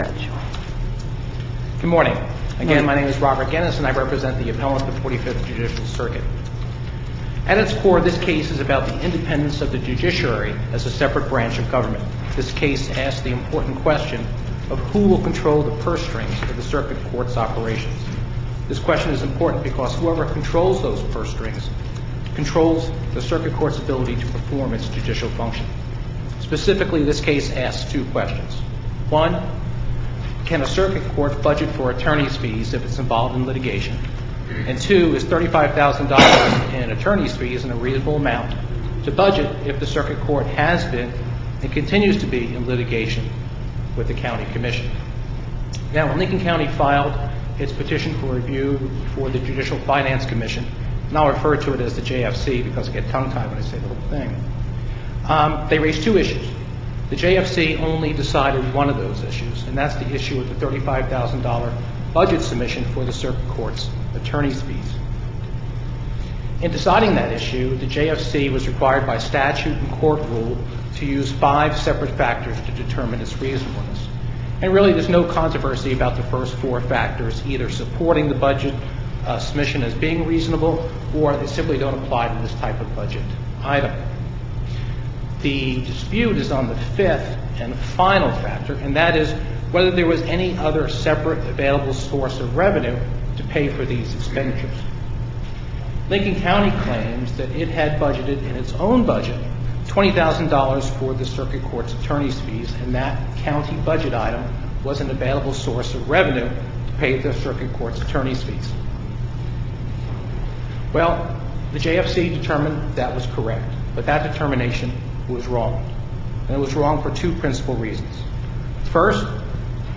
MP3 audio file of oral arguments in SC95944